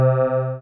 beam.wav